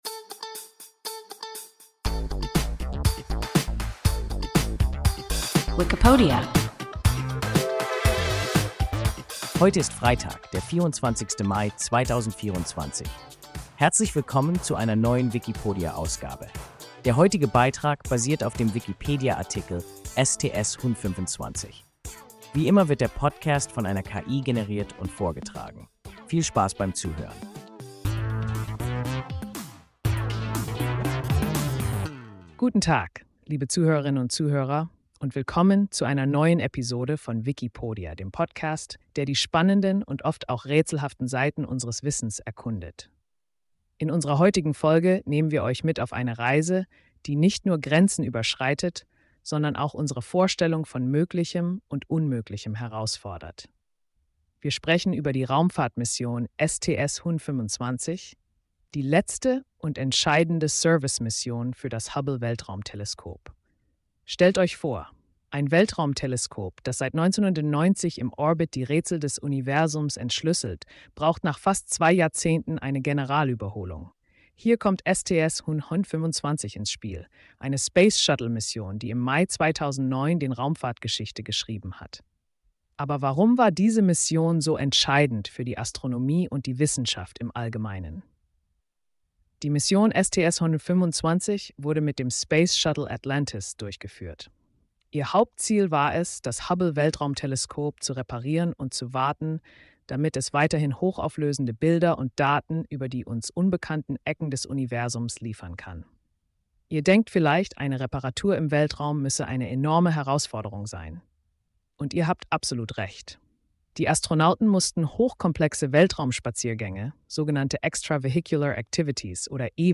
STS-125 – WIKIPODIA – ein KI Podcast